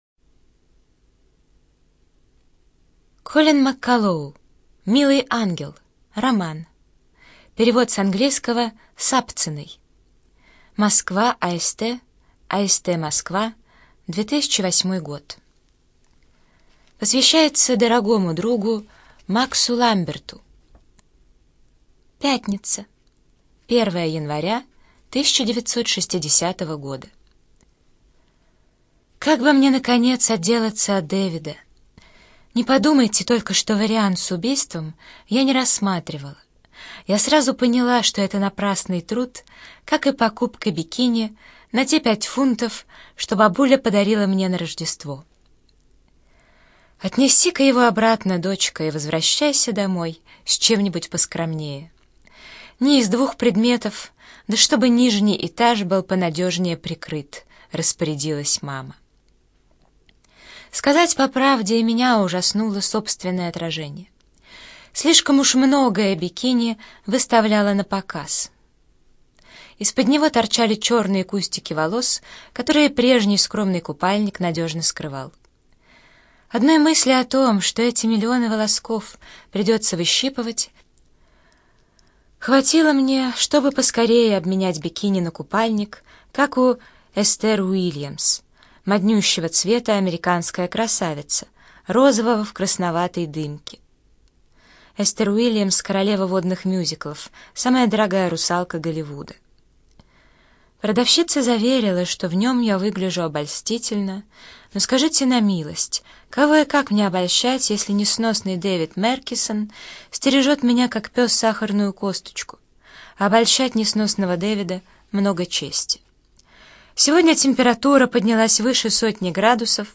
ЖанрСовременная проза
Студия звукозаписиЛогосвос